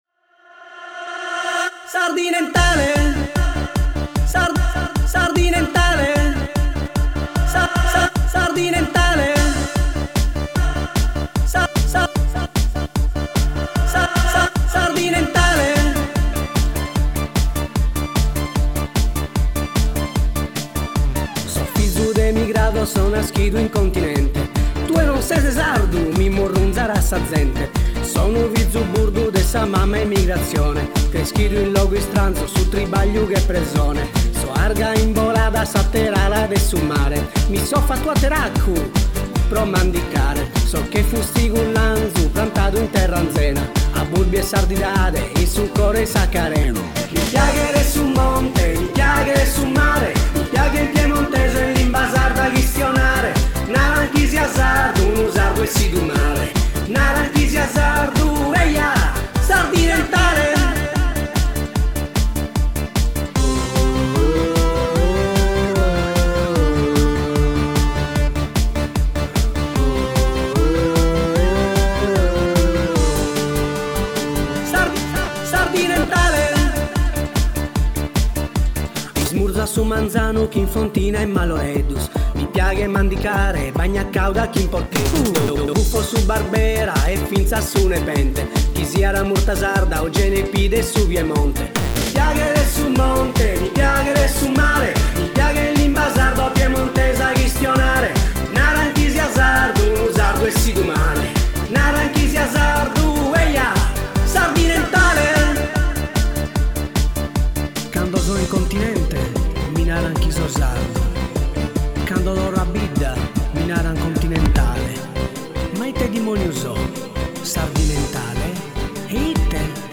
A tratti autoironico